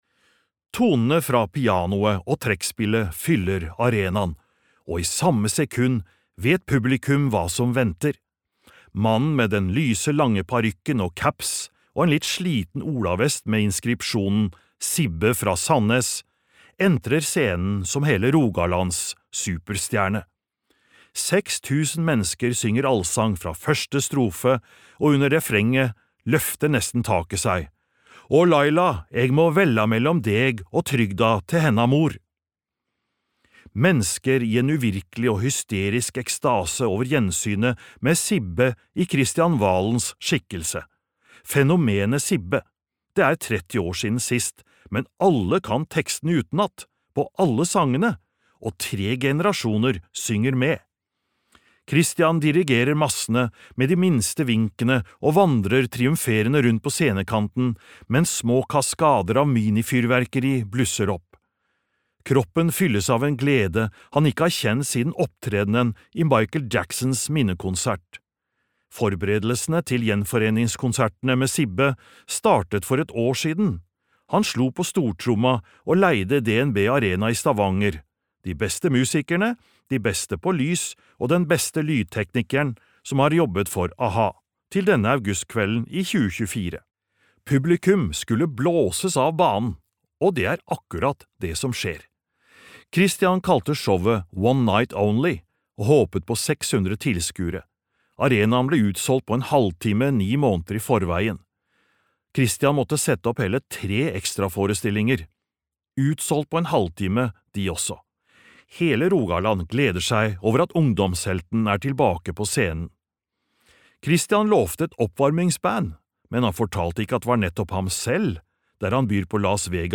Kristian Valen - fra spøk til maskinpistol - min historie (lydbok) av Per Asle Rustad